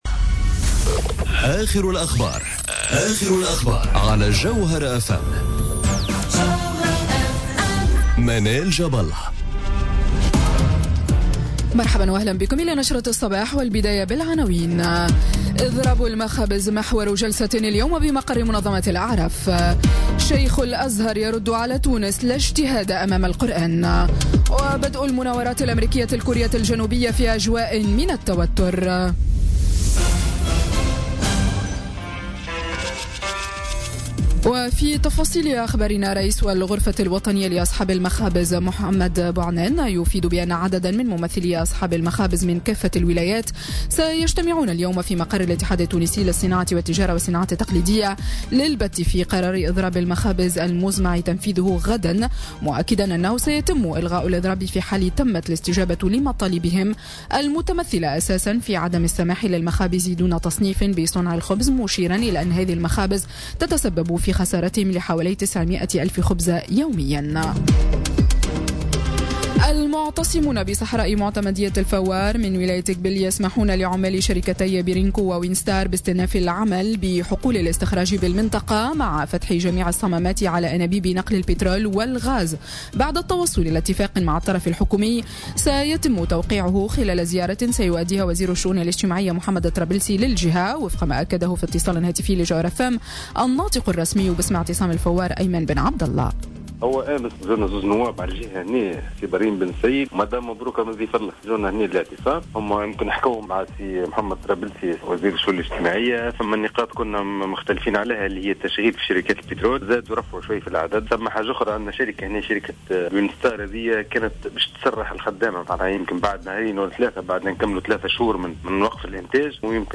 نشرة أخبار السابعة صباحا ليوم الإثنين 21 أوت 2017